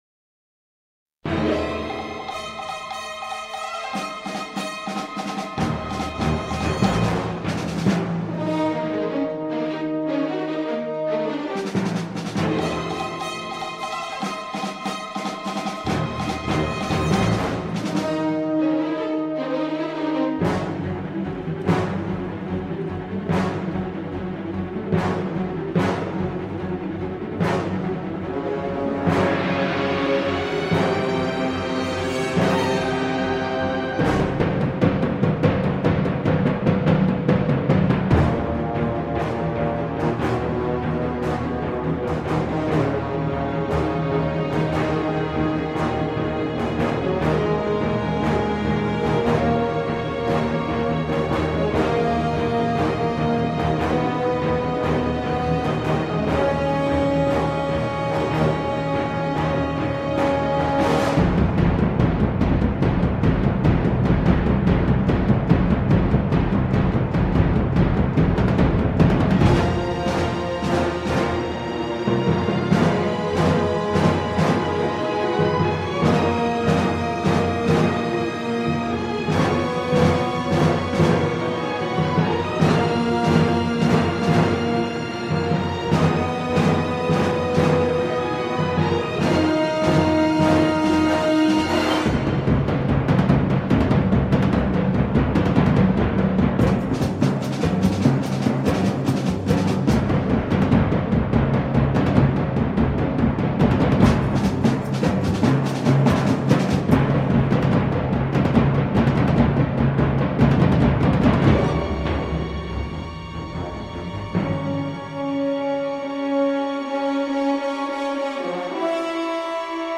cuivres survitaminés